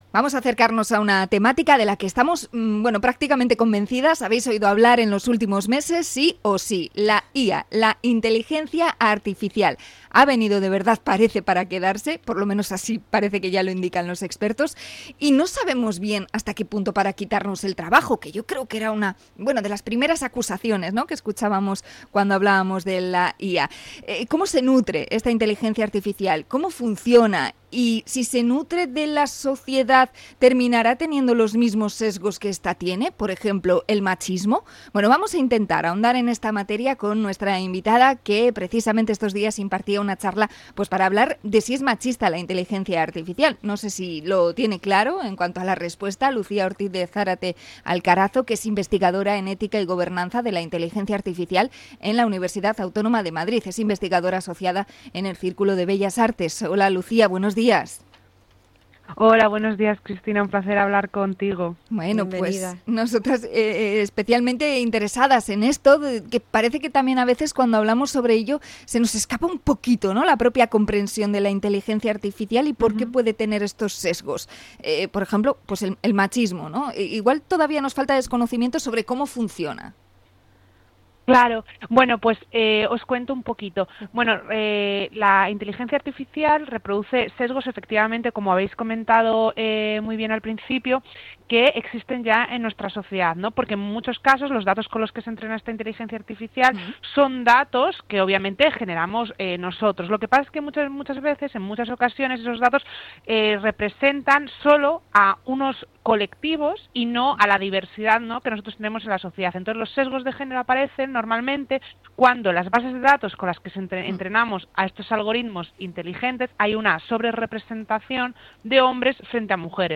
Entrevista a experta en IA sobre el sesgo machista de la Inteligencia Artificial